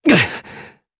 Esker-Vox_Damage_jp_01.wav